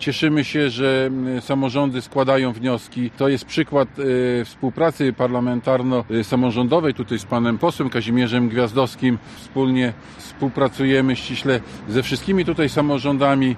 Senator Rzeczypospolitej Polskiej, Marek Komorowski dodał, że to wynik silnej współpracy: